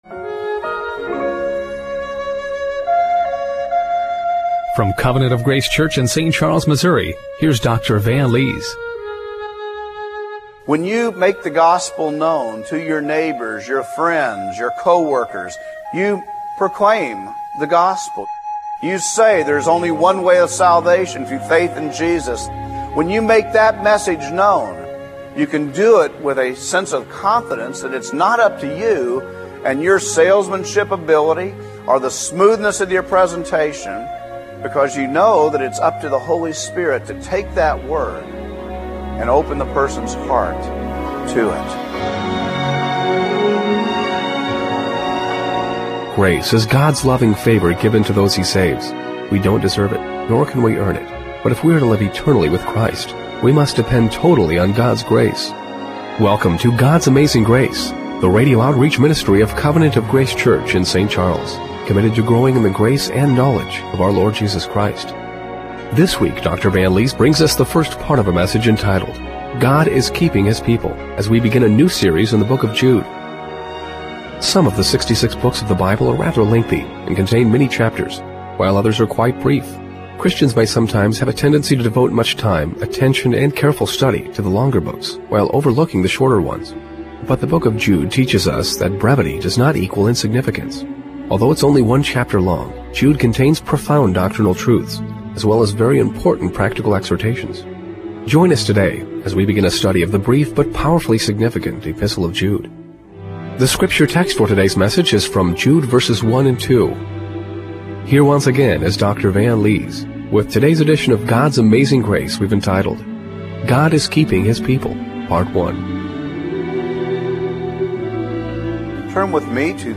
Jude 1:1-2 Service Type: Radio Broadcast Have you skipped over the book of Jude?